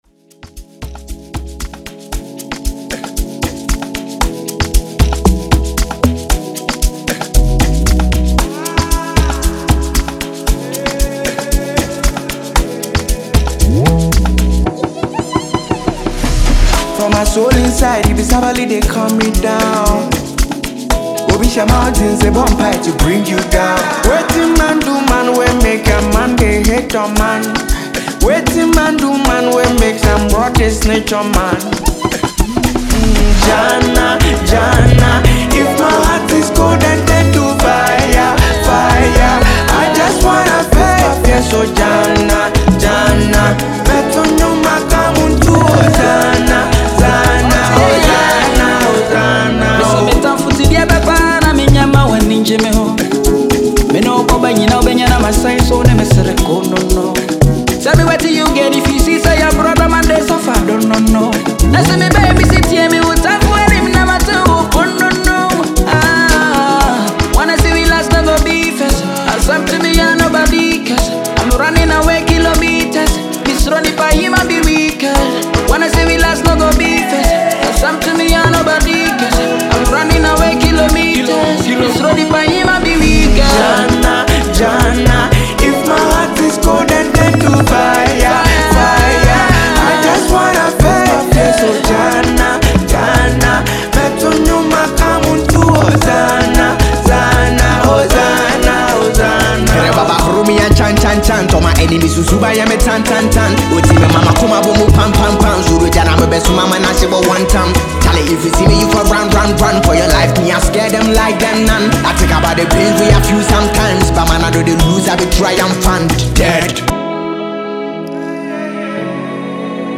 a sensational Ghanaian musician